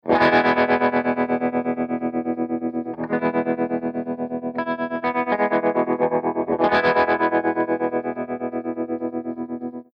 017_AC30_TREMOLO2_P90